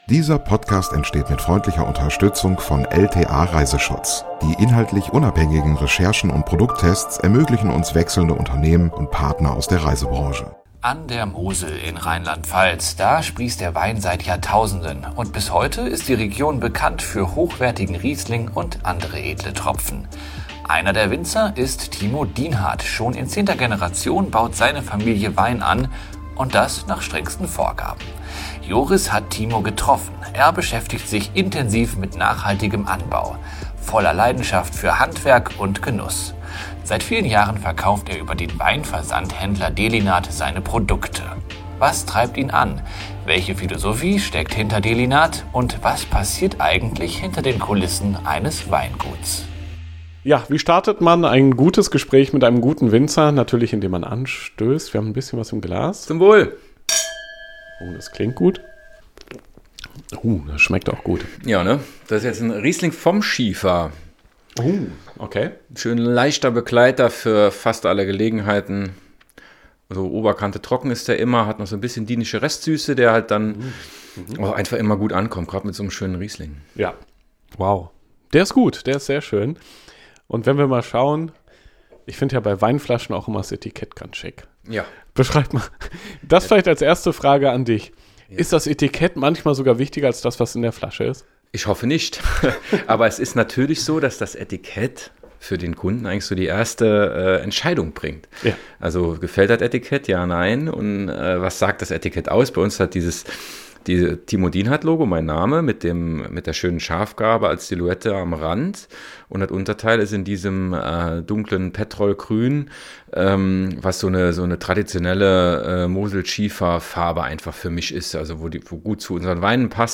Interview ~ Lieblingsreisen - Mikroabenteuer und die weite Welt Podcast